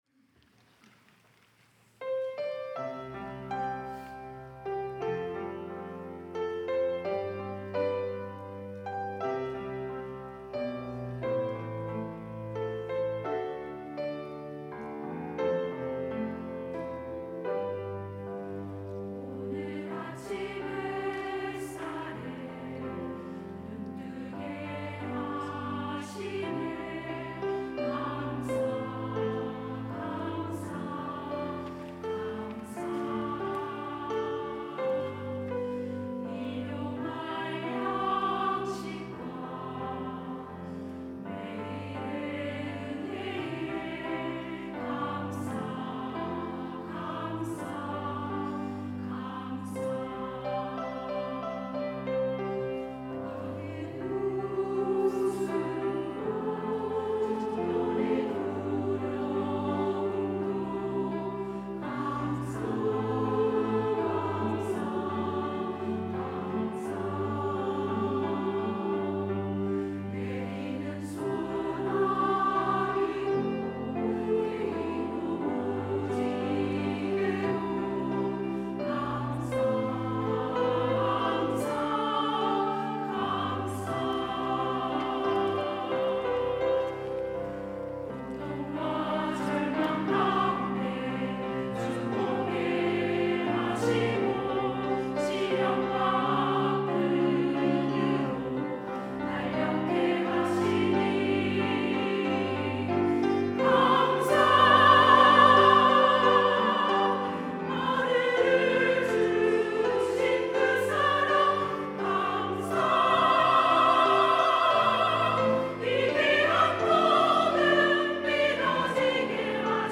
여전도회 - 언제나 감사